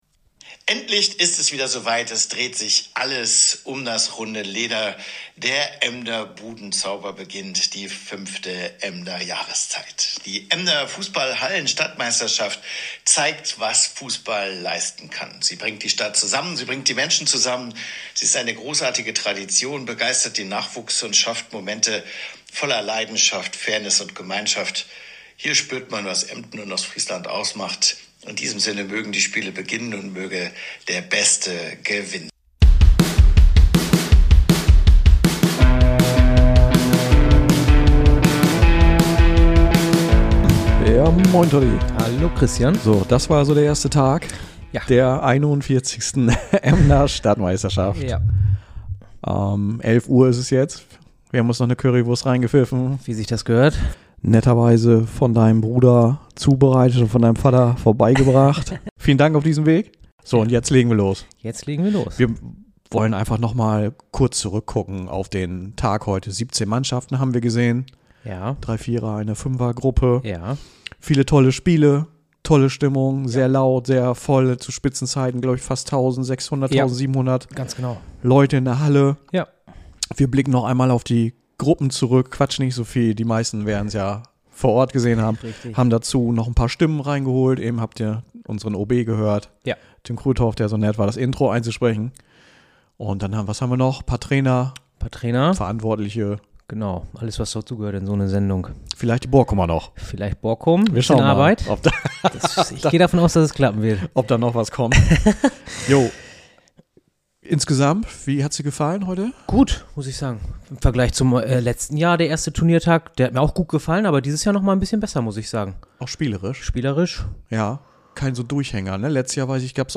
Außerdem möchten wir Emdens Oberbürgermeister Tim Kruithoff herzlich danken, der uns freundlicherweise das Intro eingesprochen hat.